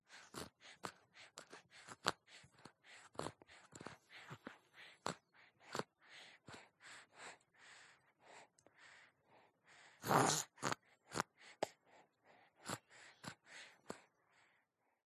Звуки детского храпа
Звук спящего новорожденного с храпом и сопением